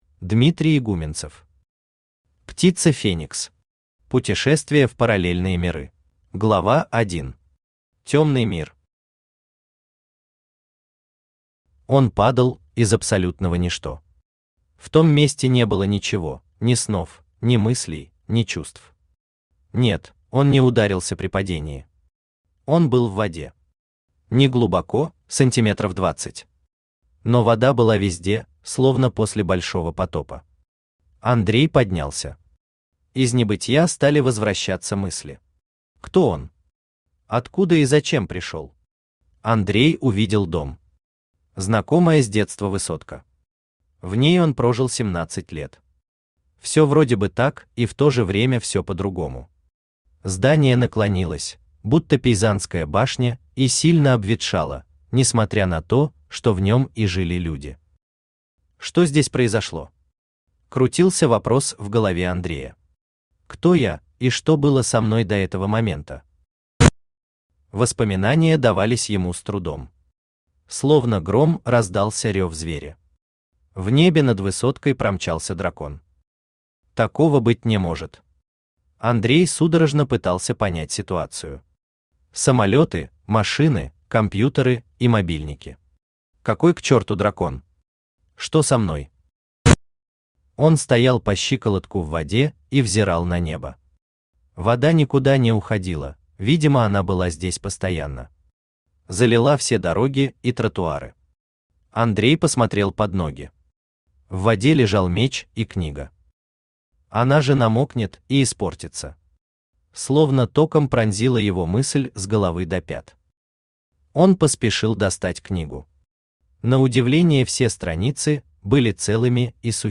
Читает: Авточтец ЛитРес
Аудиокнига «Птица Феникс. Путешествия в параллельные миры».